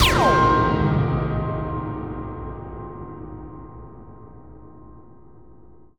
Synth Impact 25.wav